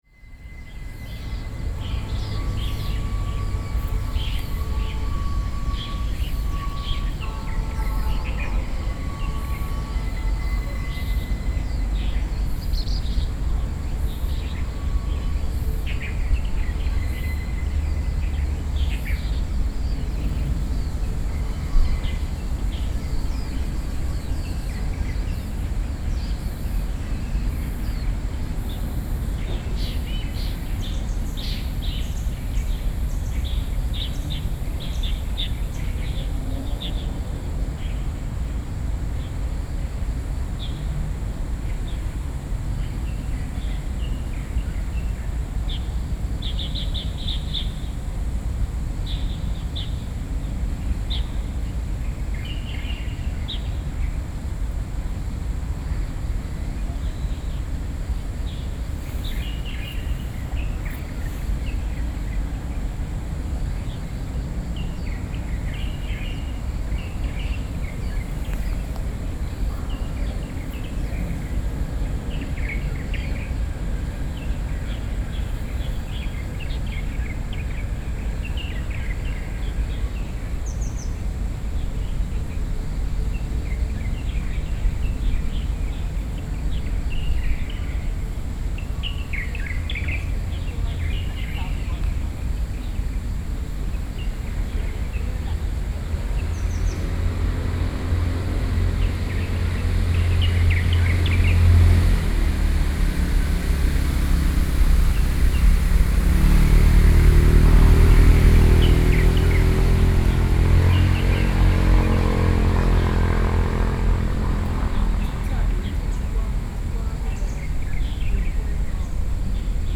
Lane 30, Section 4, Zhōngyāng North Rd, Beitou District - in the morning